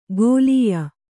♪ gōlīya